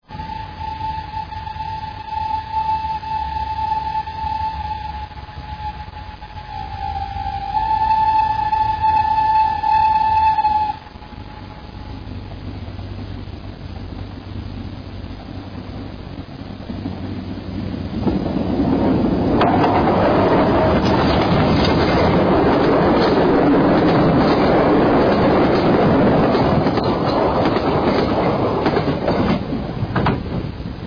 Sounds of Great Western steam locomotives